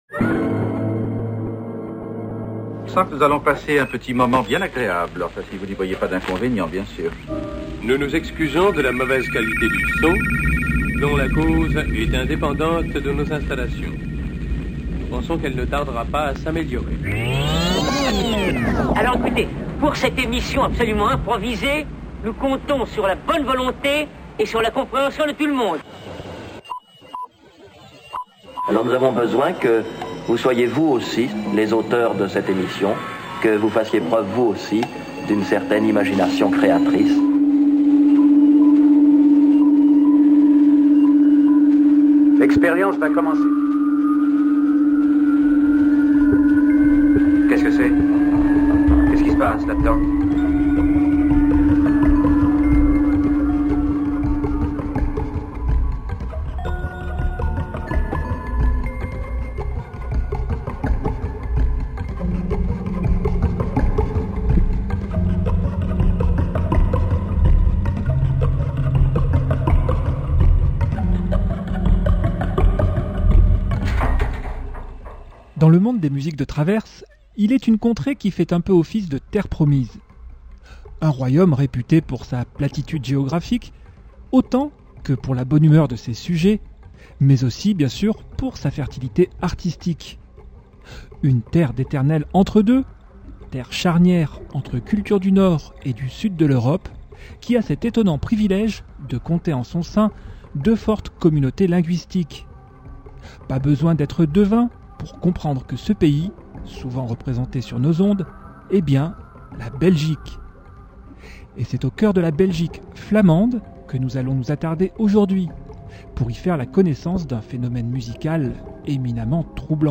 Entre cabaret post-industriel et ambient dramatique